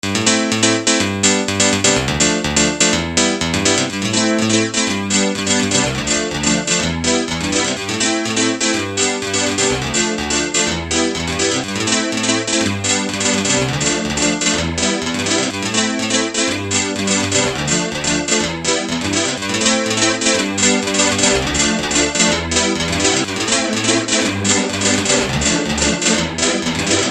Chorus
zopßr ukß╛ok chorusu - klavφr. Prvß je bez chorusu, poslednß s extrΘmnym chorusom, kde u╛ dobre poΦu╗ to odladenie a kolφsanie.
chorus_mix.mp3